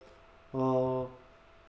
wake_word_noise